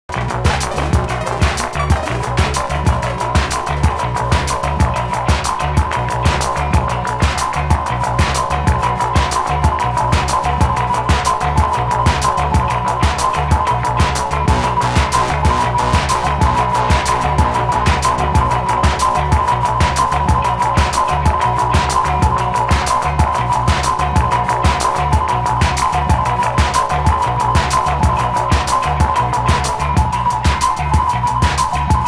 アルペジオシンセに、不穏なサウンドを加えたミドルテンポのダブ・ブレイク！